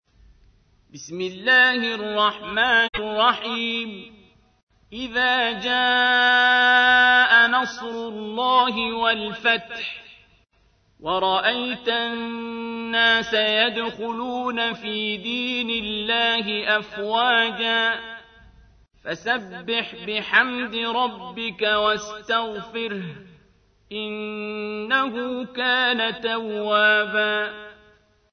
تحميل : 110. سورة النصر / القارئ عبد الباسط عبد الصمد / القرآن الكريم / موقع يا حسين